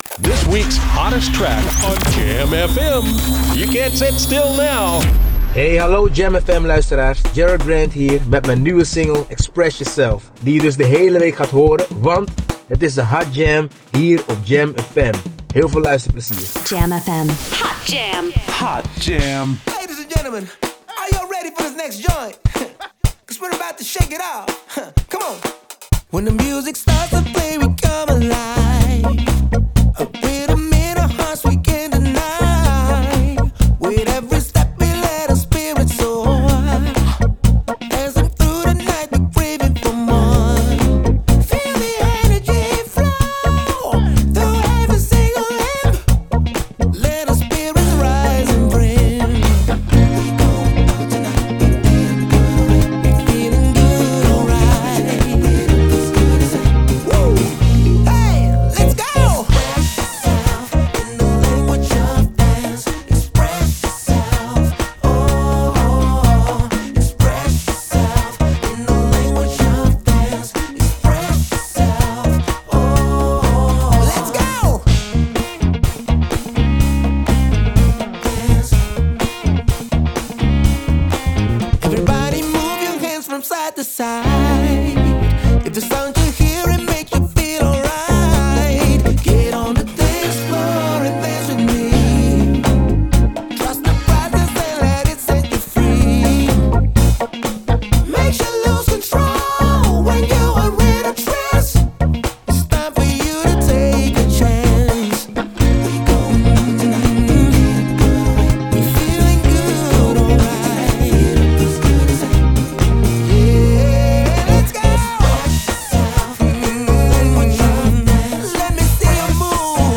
funky single